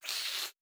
02_孤儿院走廊_猫咪嘶吼.wav